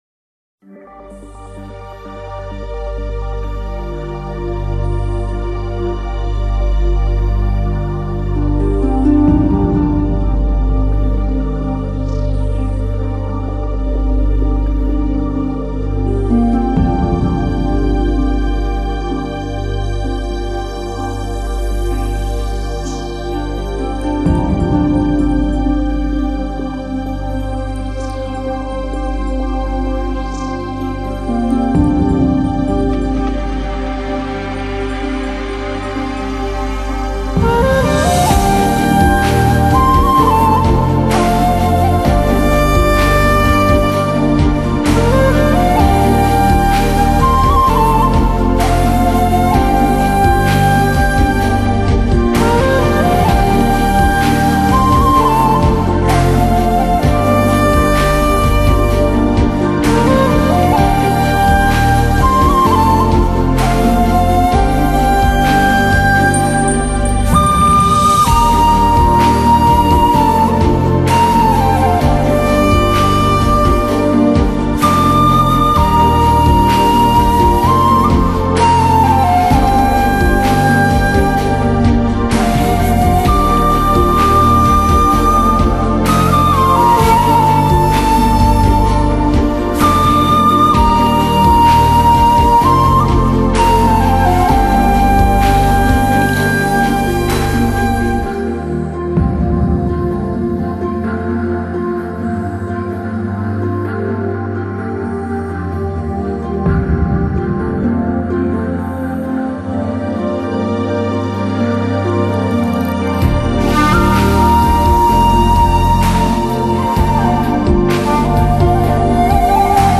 类型:NewAge 流派:Instrumental
以尺八为主演绎的音乐专辑